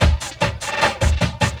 45 LOOP 07-R.wav